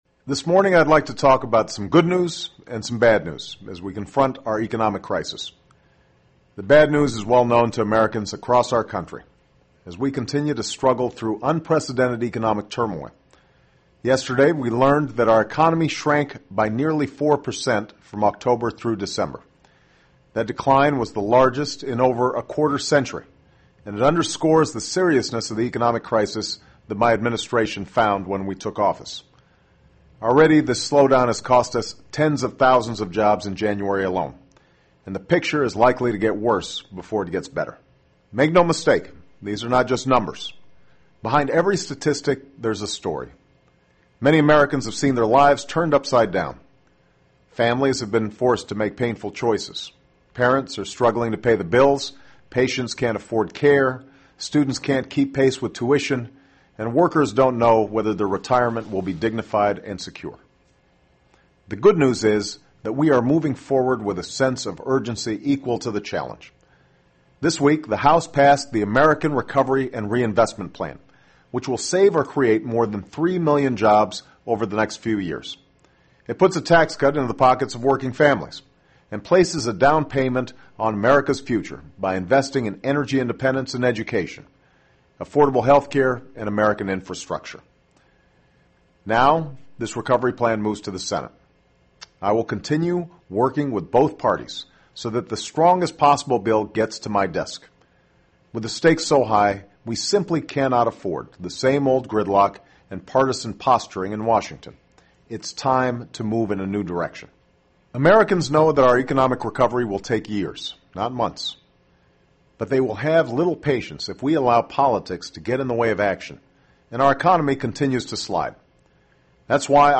【美国总统电台演说】2009-01-31 听力文件下载—在线英语听力室